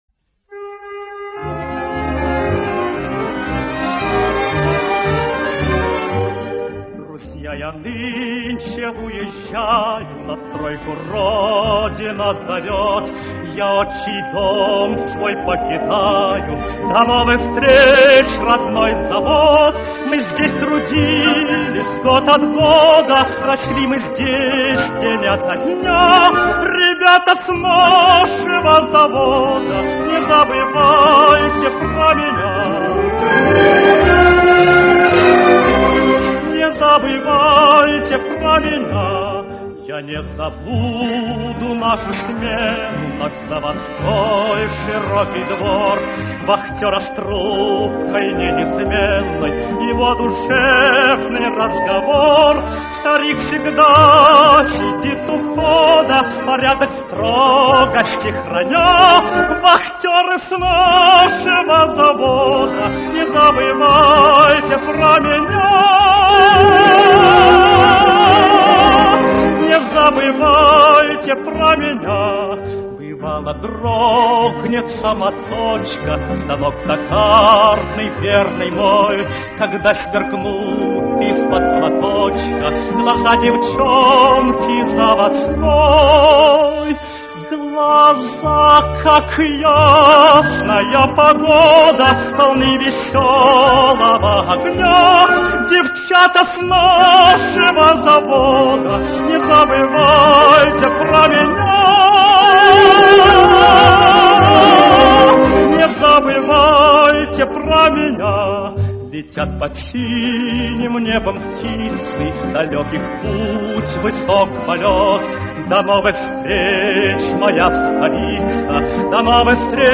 Прекрасная и редкая песня лирико-патриотическая песня.